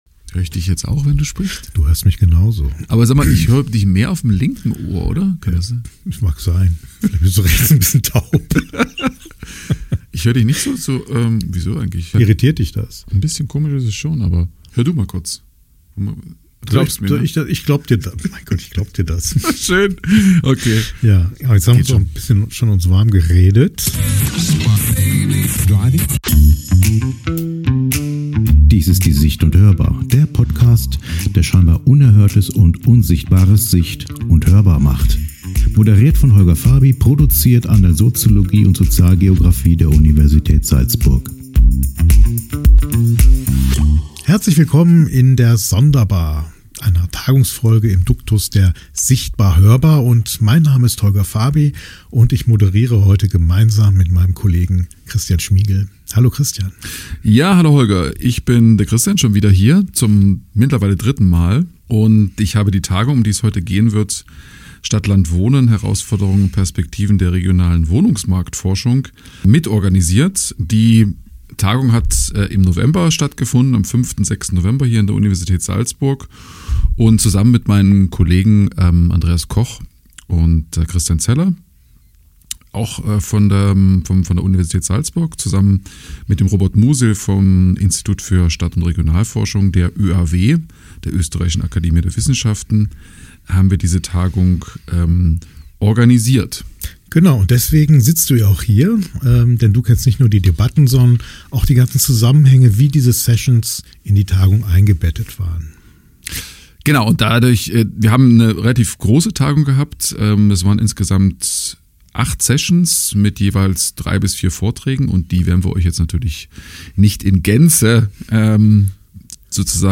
Wir reden über Gentrifizierung als Dauerzustand: mal laut, mal im Kleingedruckten, selten auf dem Wahlkampfplakat. Mit im Gepäck: O-Töne aus den Tagungssessions. Wir reisen von Bremen, Freiburg, Leipzig und Nürnberg bis ins Salzburger Andräviertel und haben drei Takeaways dabei: Gentrifizierung läuft weiter, politische Diskurse entscheiden mit, und „Stadtteil schützen“ ist nicht dasselbe wie „Wohnen sichern“.